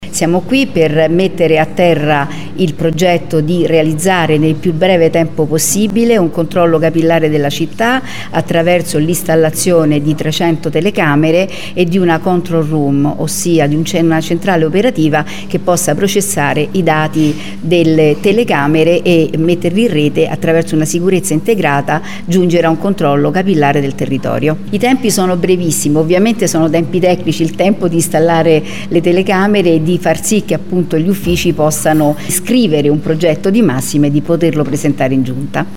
“Porto la solidarietà della Regione non solo con le parole ma anche con i fatti – ha detto Luisa Regimenti che ha spiegato le novità sulla sicurezza nel capoluogo: